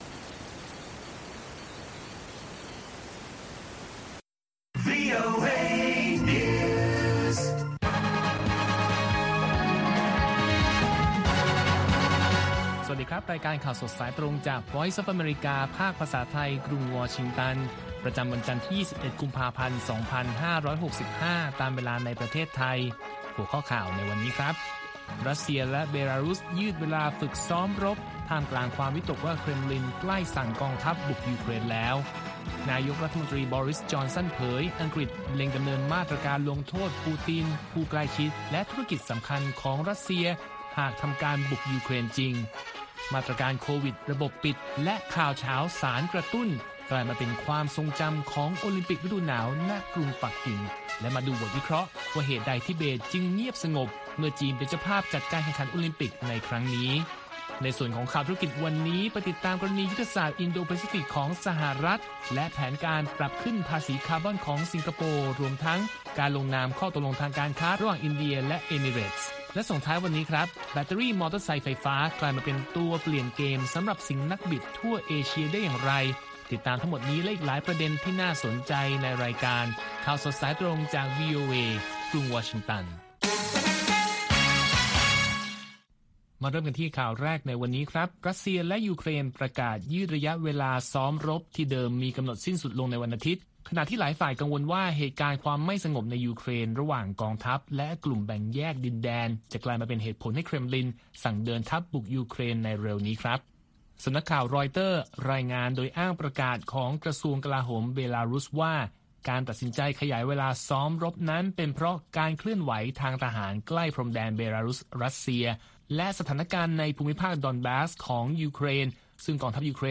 ข่าวสดสายตรงจากวีโอเอ ภาคภาษาไทย 8:30–9:00 น. ประจำวันจันทร์ที่ 21 กุมภาพันธ์ 2565 ตามเวลาในประเทศไทย